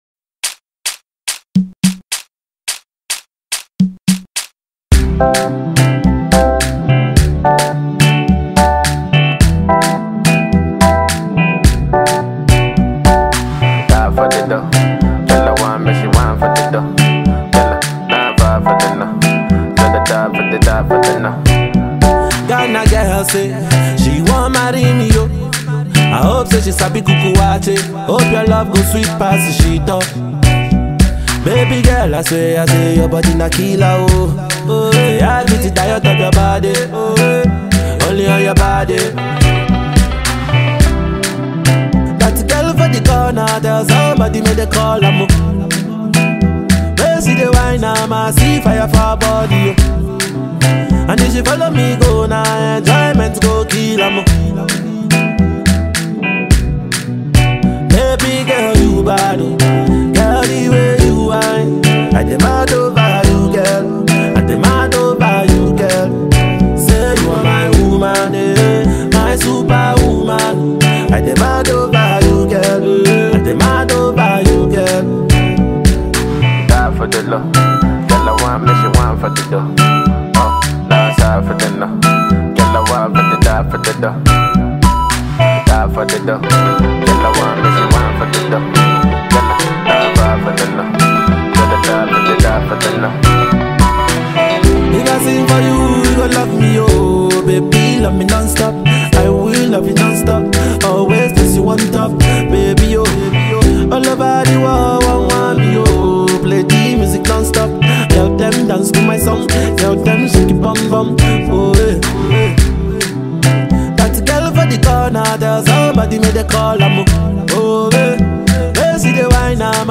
романтическим гимном